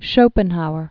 (shōpən-houər), Arthur 1788-1860.